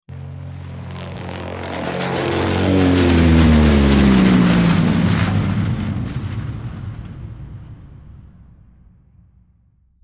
Airplane Takeoff 02
Airplane_takeoff_02.mp3